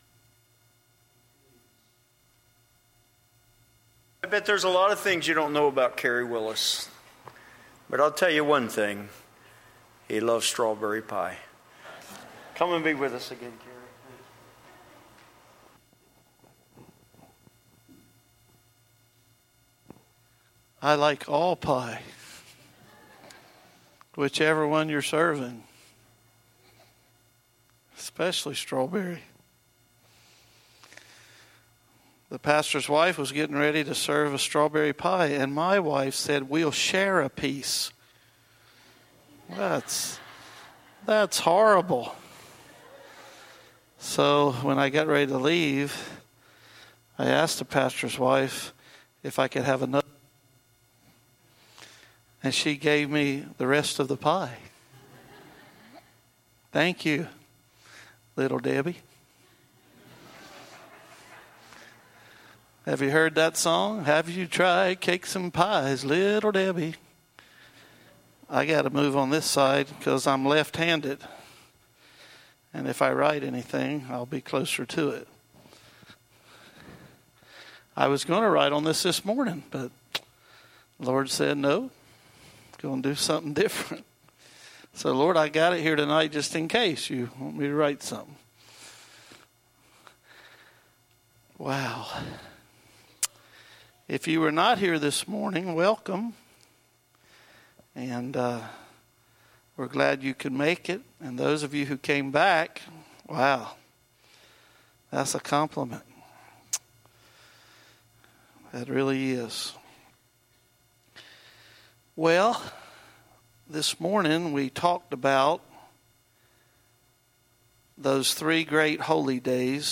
6-9-19-6pm-Sermon.mp3